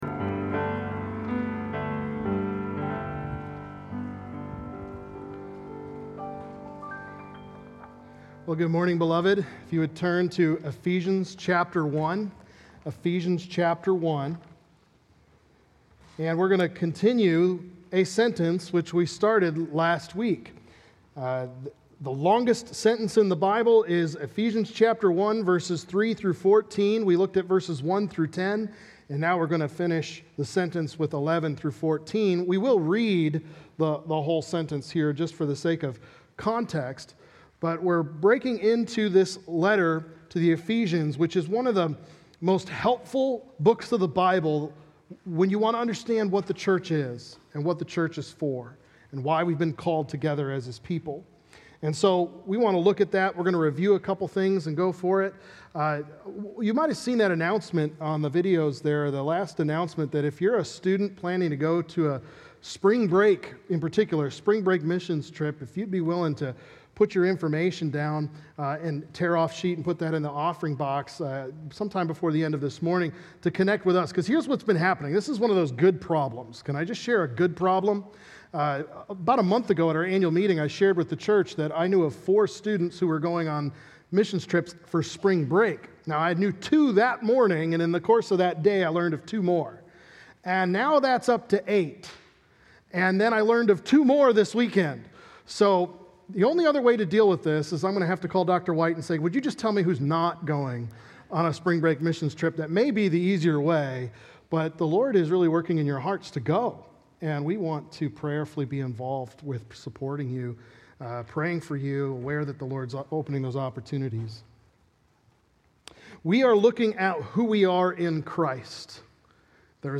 To The Praise Of His Glory | Baptist Church in Jamestown, Ohio, dedicated to a spirit of unity, prayer, and spiritual growth